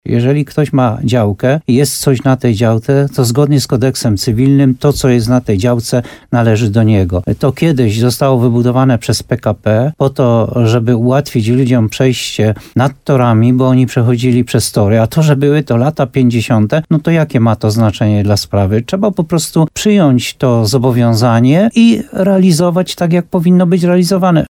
- Spółka PKP twierdzi, że kładka nad torami przy dworcu w Muszynie nie należy do niej - mówi Jan Golba burmistrz Muszyny.